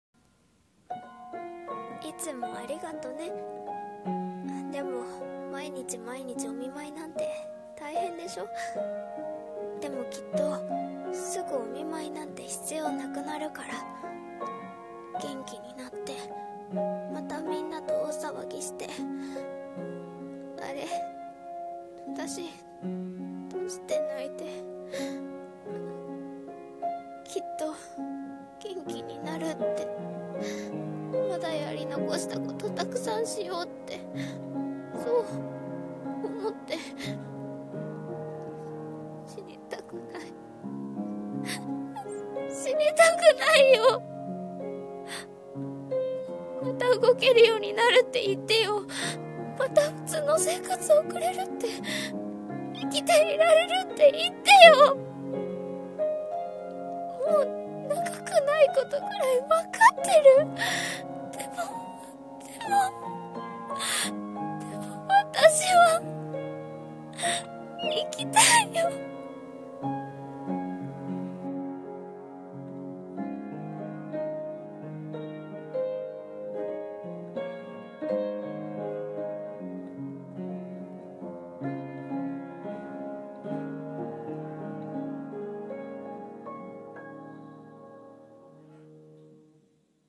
《1人声劇》